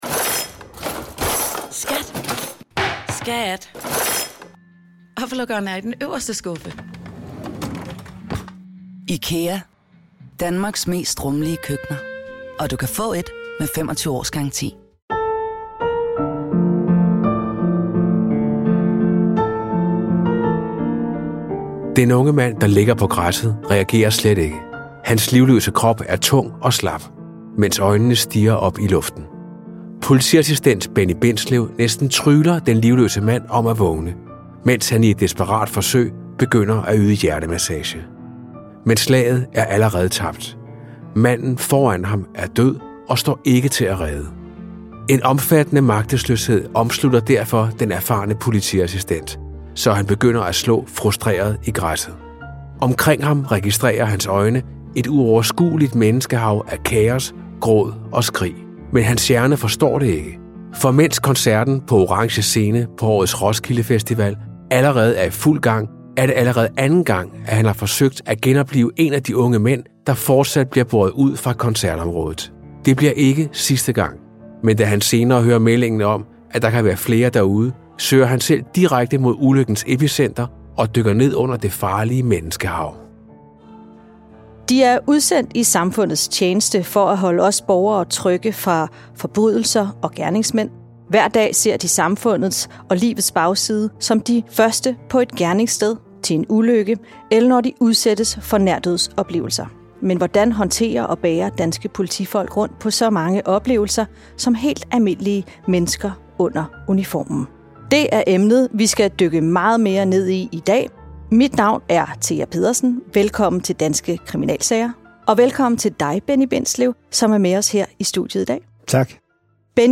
I denne episode går vi tæt på en af personerne bag politiuniformen, som fortæller sin personlige historie.